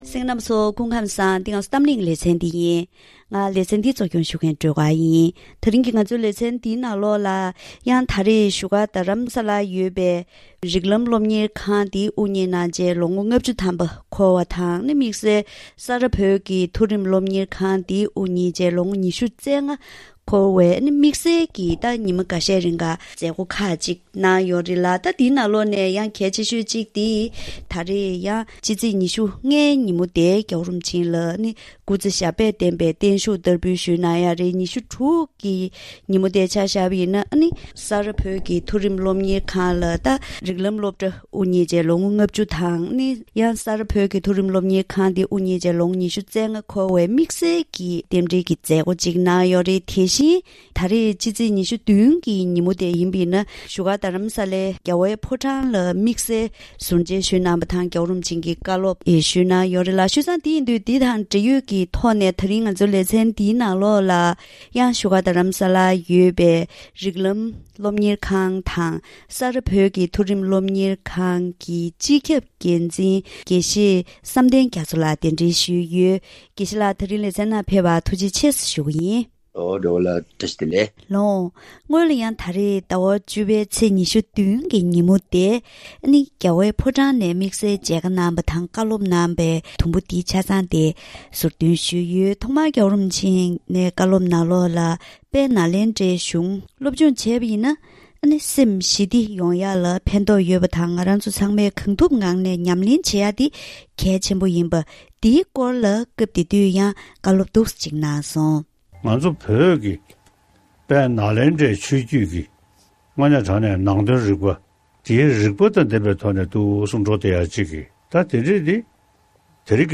ལེ་ཚན་ནང་འདས་པའི་དུས་ཚོད་ནང་སློབ་གསོའི་གོམ་སྟབས་དང་གྲུབ་འབྲས། མ་འོངས་པའི་འཆར་གཞི་སོགས་ཕྱོགས་མང་པོའི་ཐོག་འབྲེལ་ཡོད་དང་ལྷན་དུ་བཀའ་མོལ་ཞུས་པ་ཞིག་གསན་རོགས་གནང་།